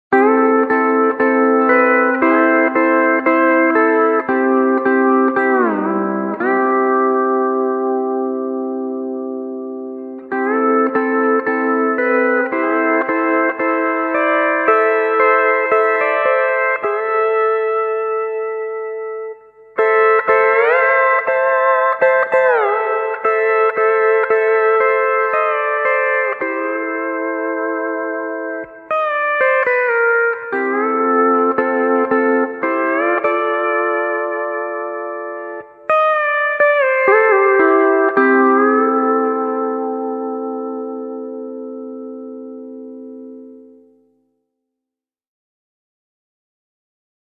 Všechna cvičení jsou nahrána na přiloženém CD.
Ukázka CD steel (mp3)